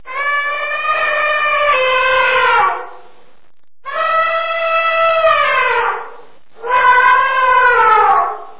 ijstijd_Elephant.mp3